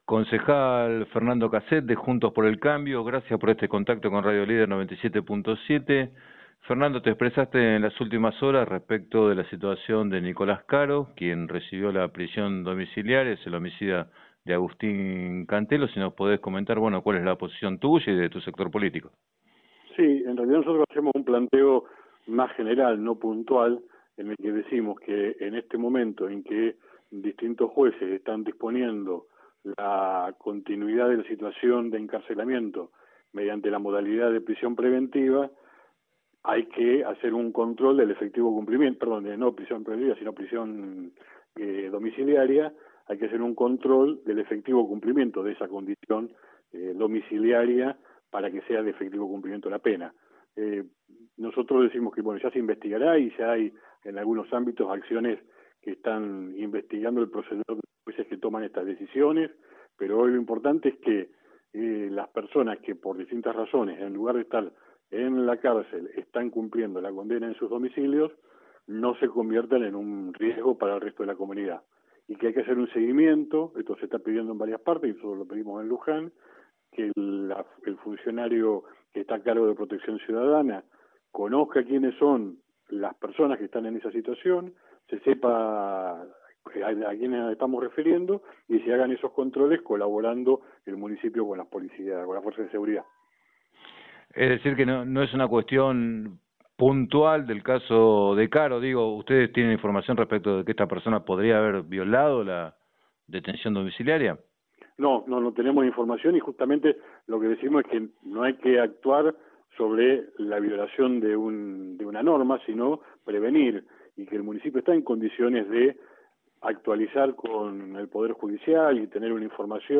En declaraciones a Radio Líder 97.7, Casset consideró que el Municipio debe ocuparse de controlar esos casos.